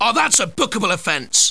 Index of /swos-soundset/ENGLISH commentary/